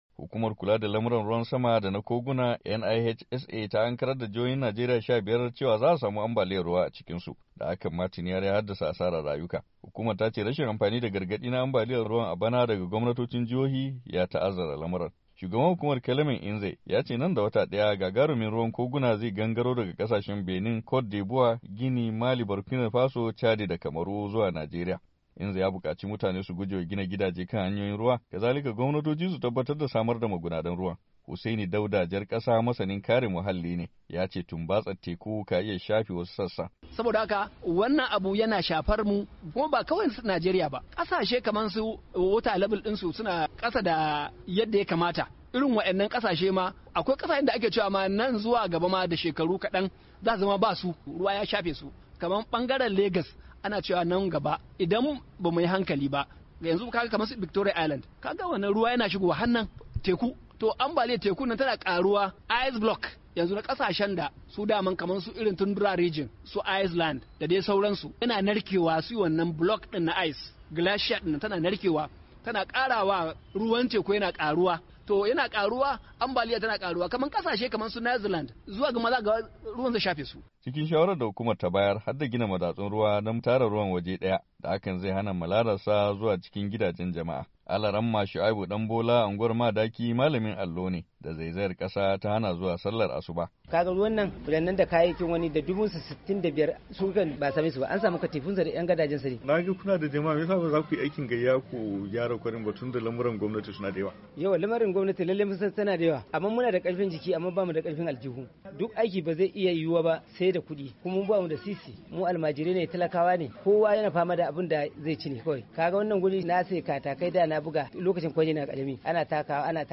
rahoton
daga Abuja